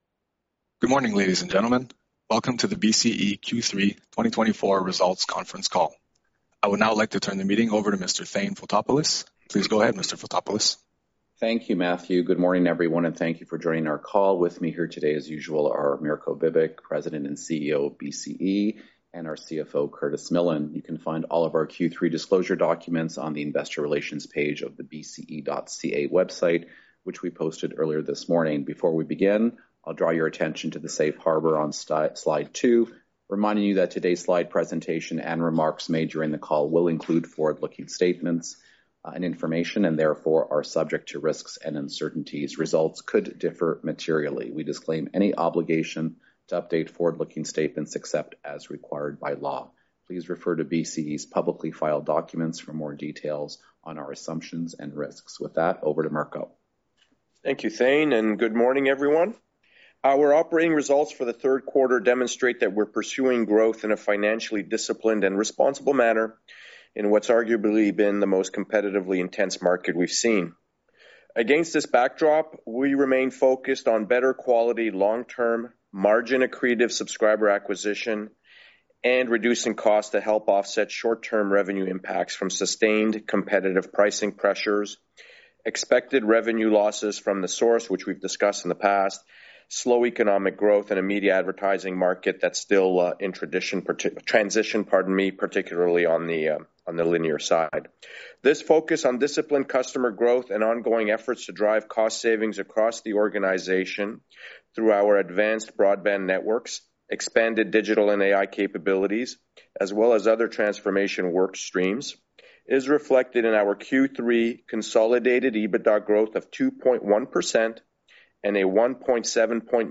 Téléconférence sur les résultats de BCE pour le T3 2024